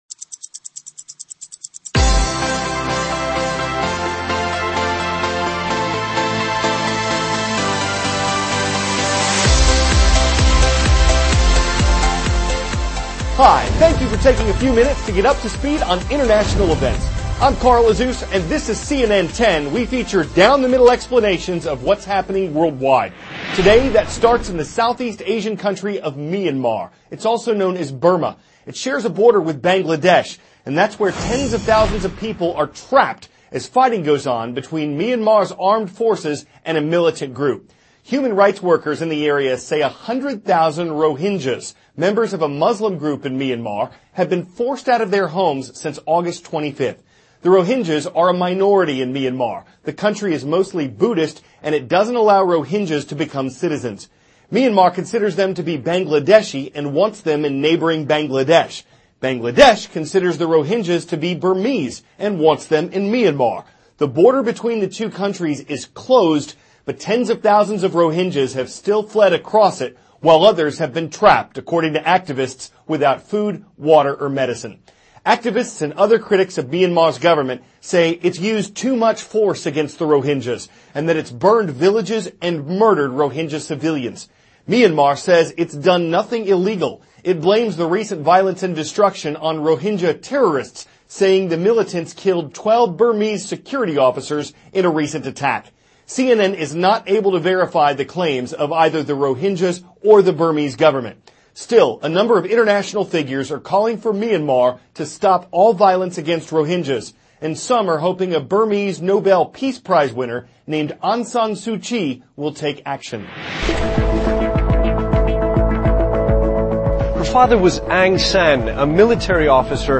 CARL AZUZ, cnn 10 ANCHOR: Hi.